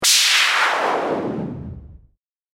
魔法 | 無料 BGM・効果音のフリー音源素材 | Springin’ Sound Stock
収束.mp3